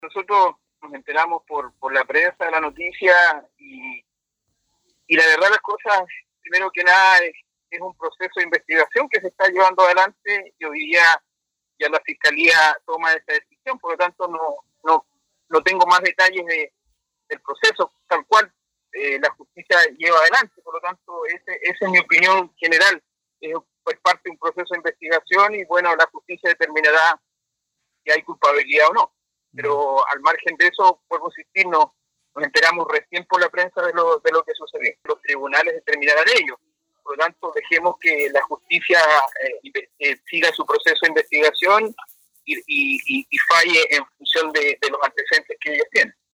En el plano local, el consejero regional por Chiloé, Francisco Cárcamo, entregó la responsabilidad de aclarar este caso a la justicia.